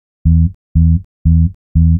TSNRG2 Off Bass 018.wav